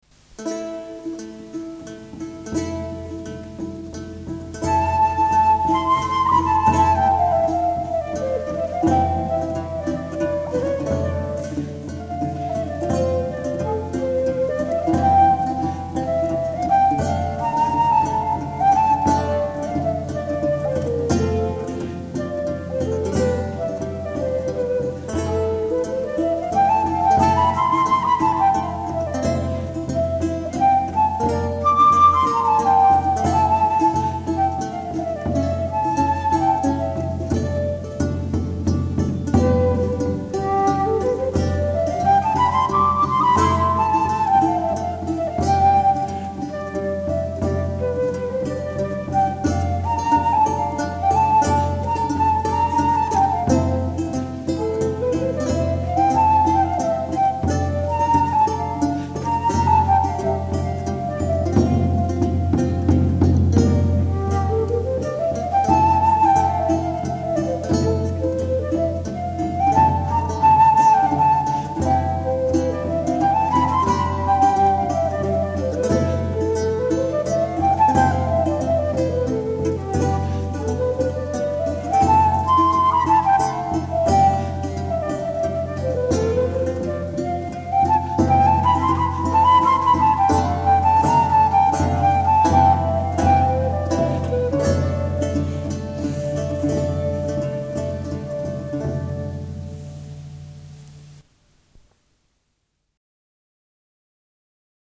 che si dedica alla ricerca nel campo delle musiche medioevali, rinascimentali e barocche, usando ricostruzioni di strumenti dell’epoca.
15-Danza-4.mp3